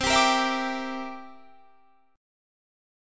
C9 Chord
Listen to C9 strummed